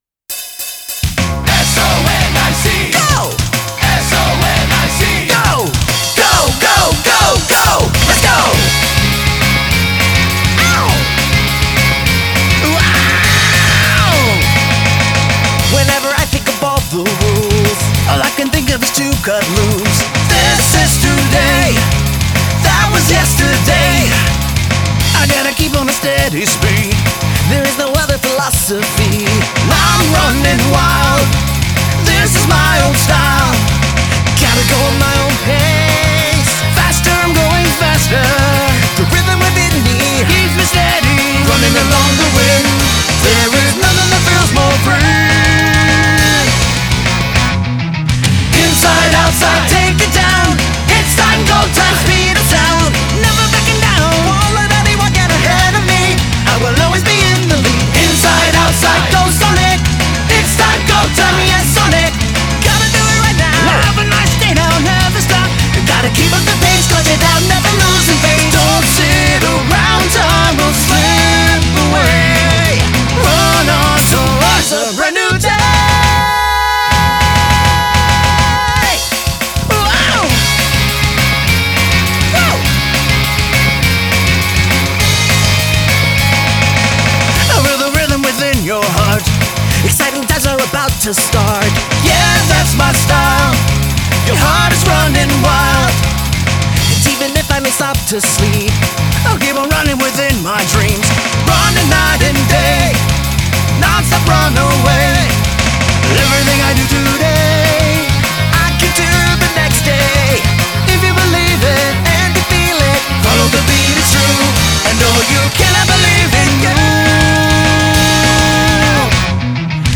Vocals
Chants